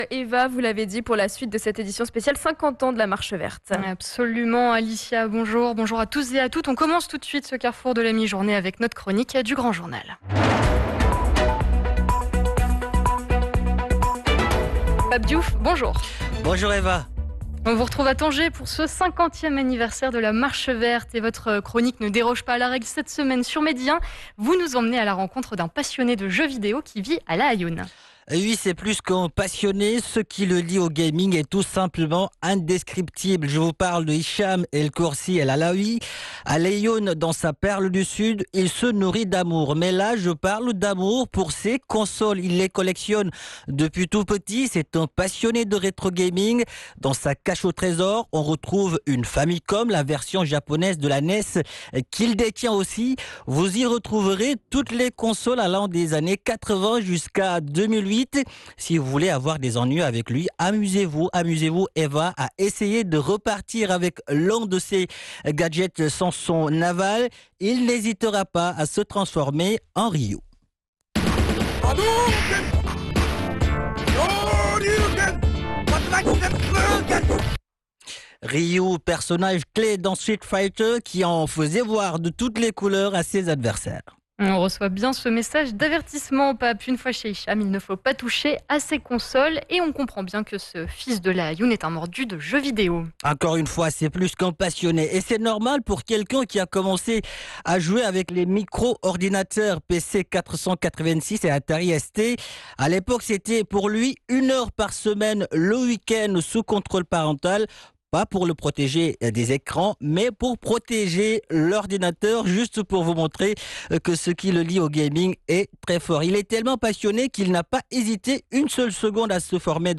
Featured on national radio, bringing visibility to Southern Morocco's emerging gaming scene and demonstrating that the industry can grow beyond major urban centers.